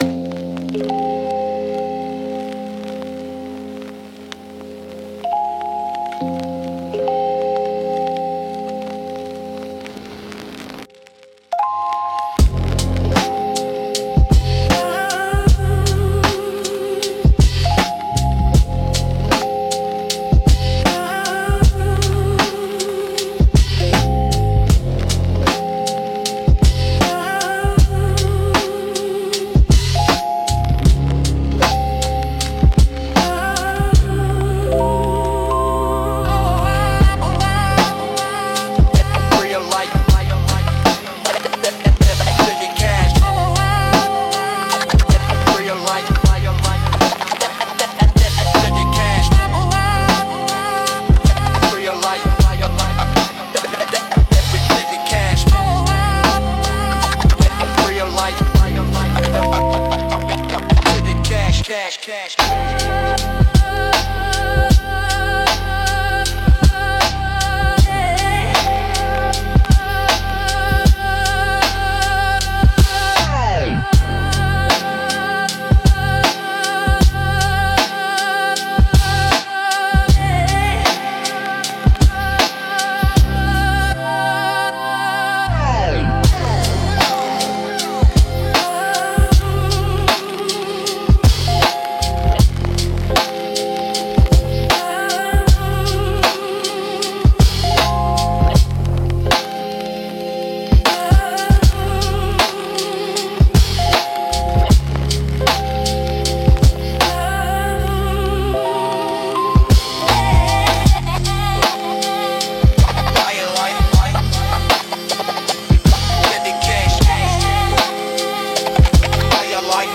Instrumental - Grayscale Glide